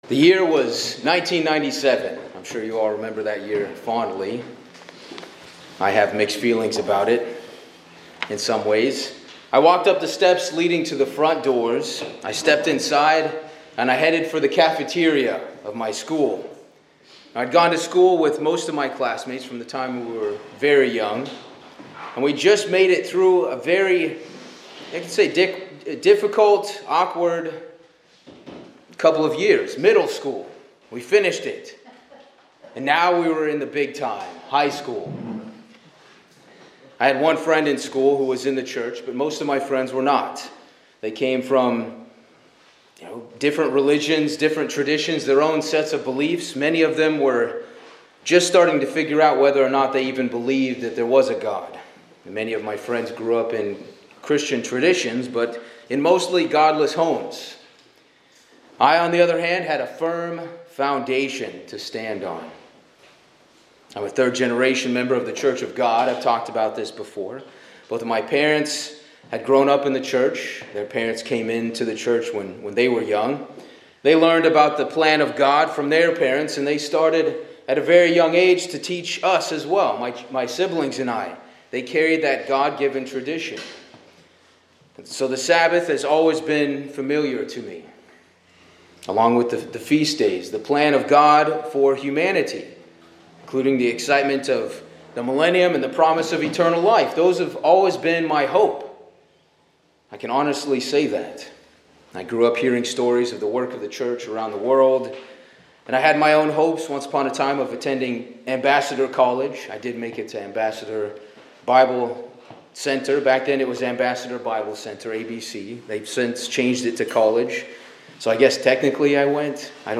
This sermon reflects on the journey of personal faith and spiritual growth. It emphasizes the importance of building on a firm foundation of belief, taking ownership of one's calling, drawing close to God, being prepared to work spiritually, relying on Jesus Christ as the cornerstone, and remembering the faithful who came before.
Given in Hartford, CT